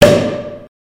Soundeffekte